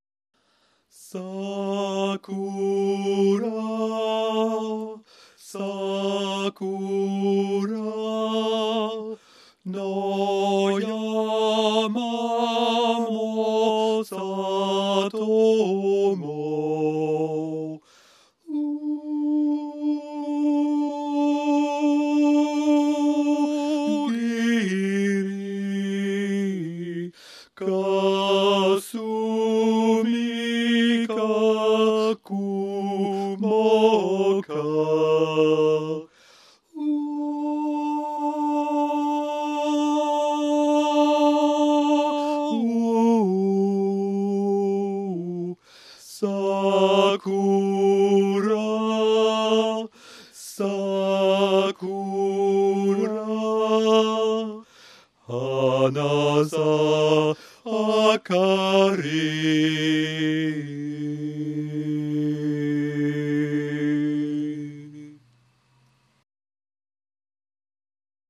Soprano
SakuraSoprano.mp3